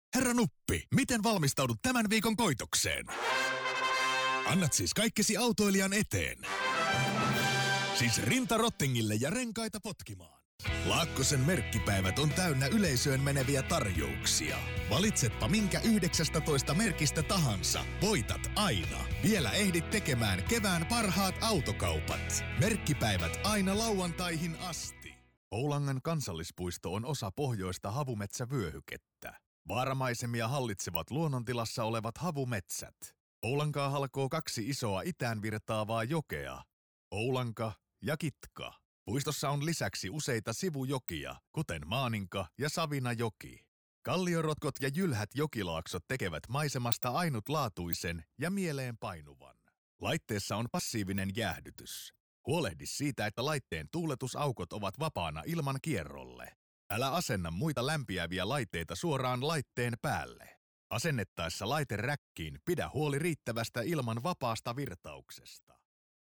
Professionelle Sprecher und Sprecherinnen
Männlich